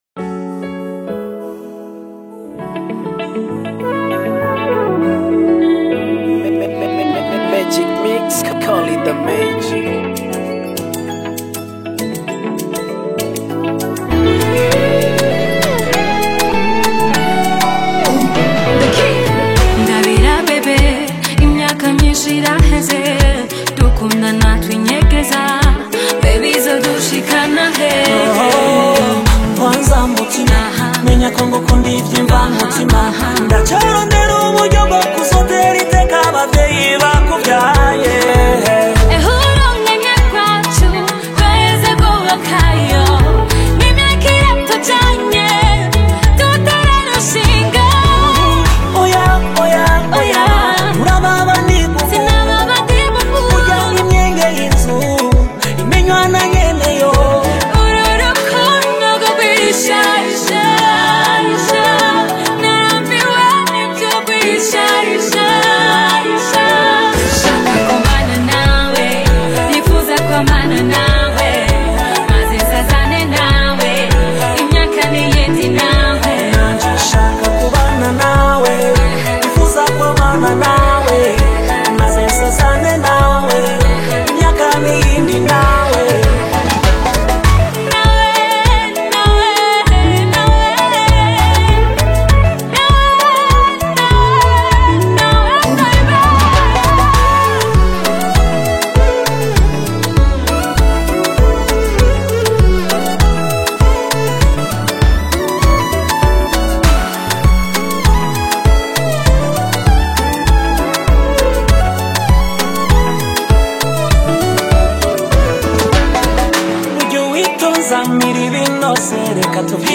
Umuririmvyikazi